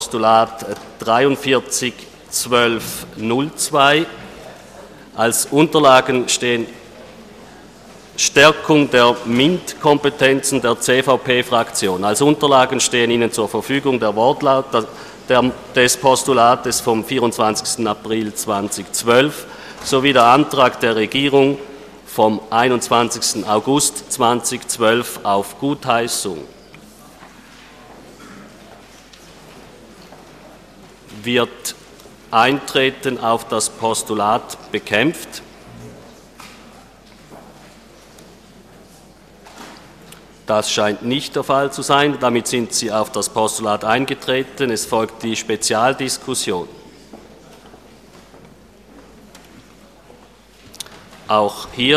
24.9.2012Wortmeldung
Session des Kantonsrates vom 24. und 25. September 2012